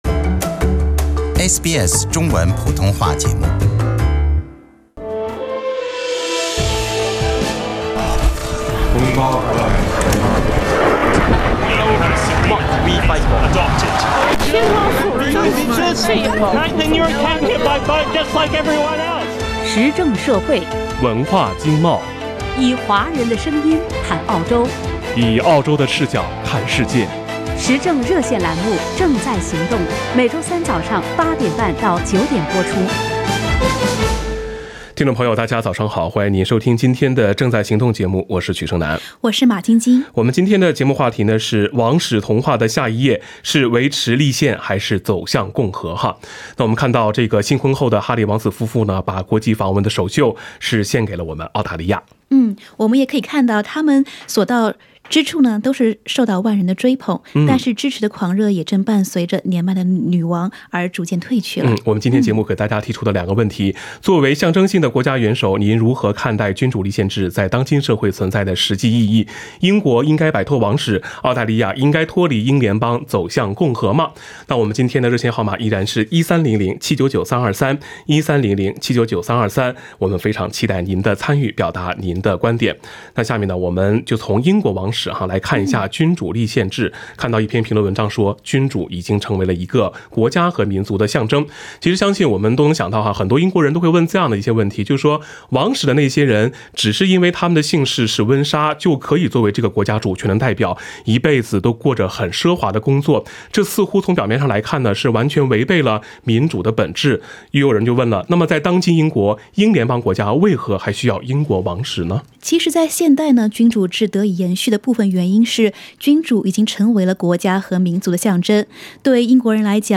作为象征性国家元首，您如何看待君主立宪制在当今社会存在的实际意义？英国应该摆脱王室，澳大利亚应该脱离英联邦走向共和吗？ 时政热线节目《正在行动》逢周三上午8点30分至9点播出。